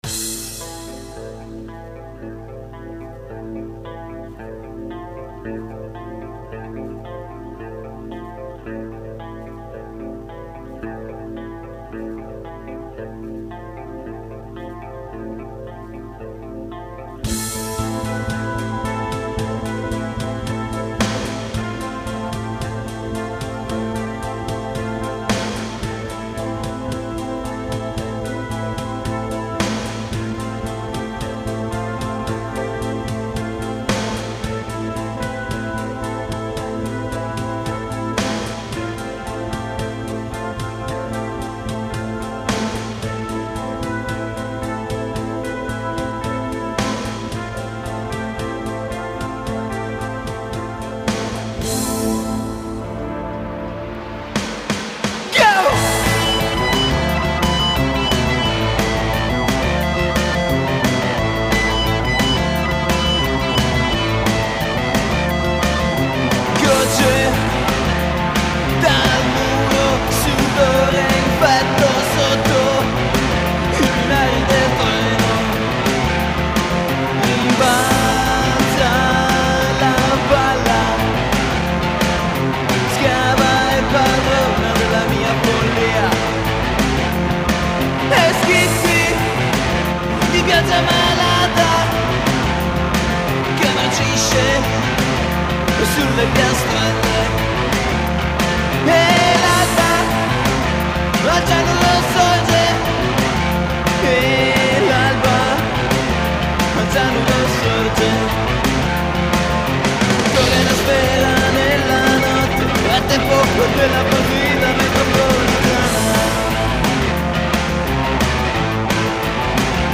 Vocals
Guitar
Bass
Drums